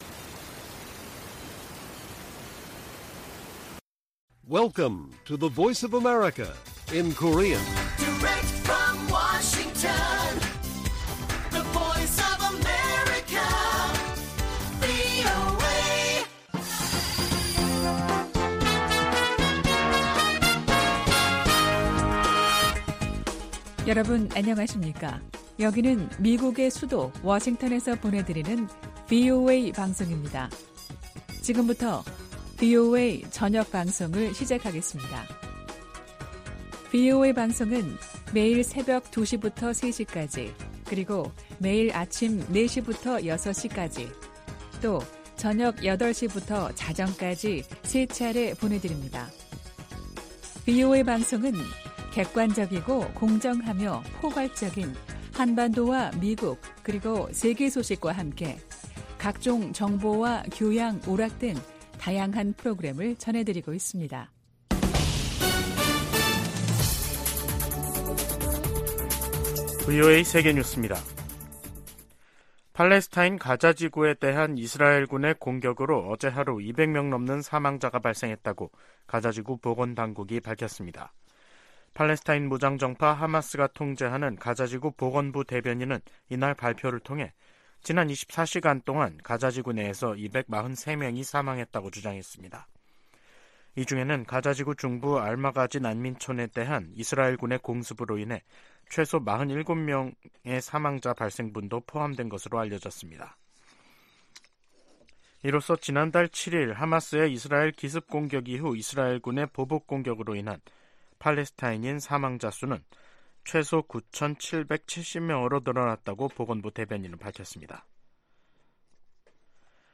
VOA 한국어 간판 뉴스 프로그램 '뉴스 투데이', 2023년 11월 6일 1부 방송입니다. 미 국방부는 북한이 미 대륙간탄도미사일 '미니트맨3' 시험발사를 비난한 데 대해 북한의 군사적 위협을 지적했습니다. 미 상원의원들이 북한과 러시아 간 군사 협력 확대가 전 세계를 위협한다며 단호한 국제적 대응을 촉구했습니다. 유엔 식량농업기구(FAO)는 17년재 북한을 외부의 식량 지원 필요국으로 지정했습니다.